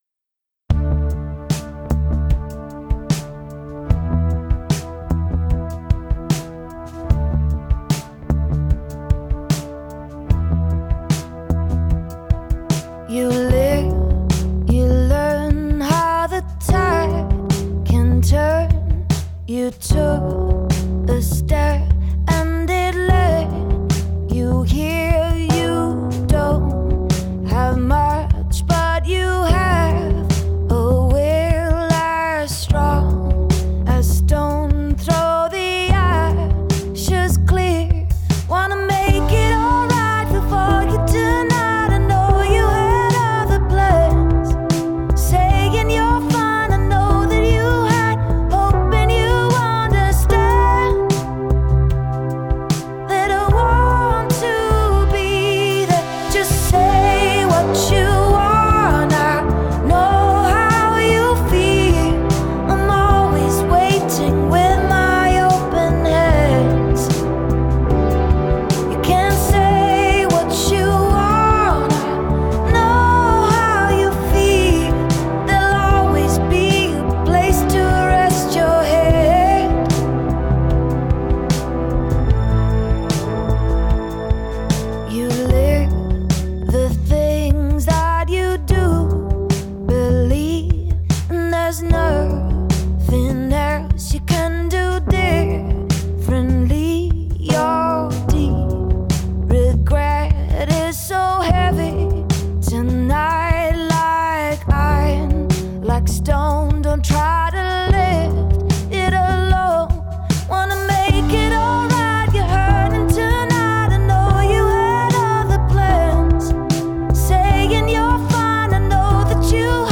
Жанр: pop · female vocalists · indie · singer-songwriter